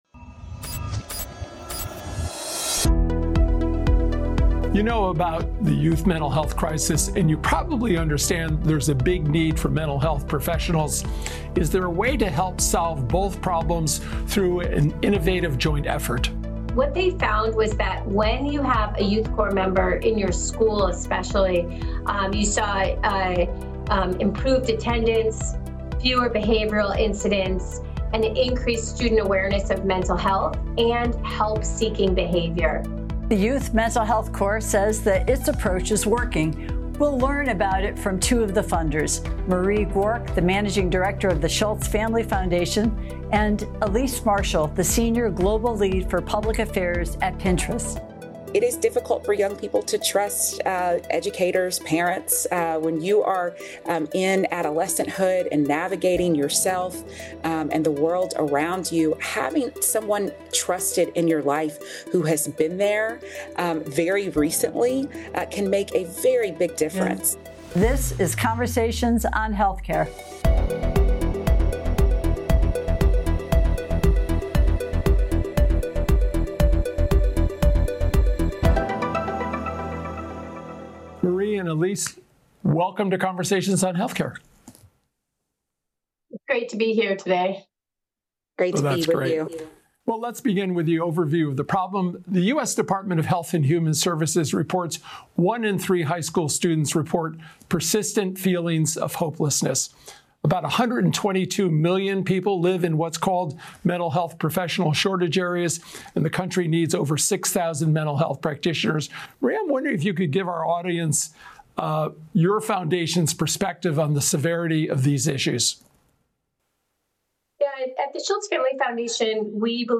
” bring their extensive experience to the conversation.